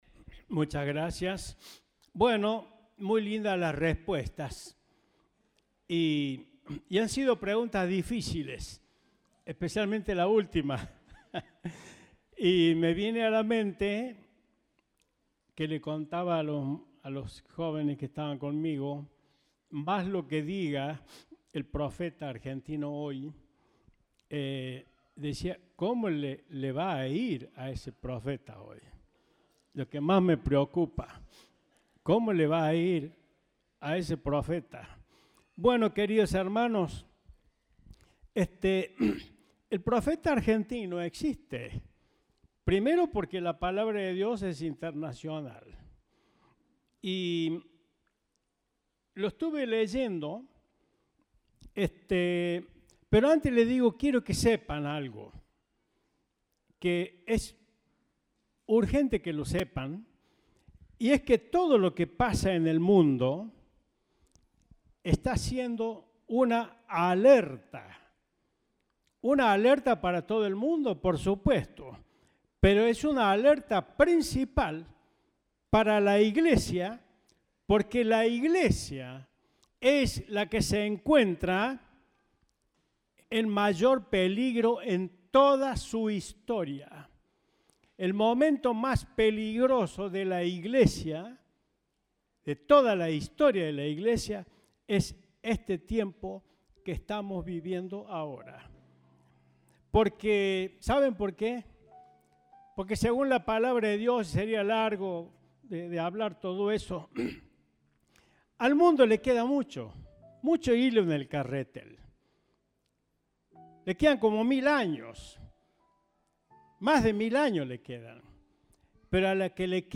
Compartimos el mensaje del Domingo 11 de Diciembre de 2022.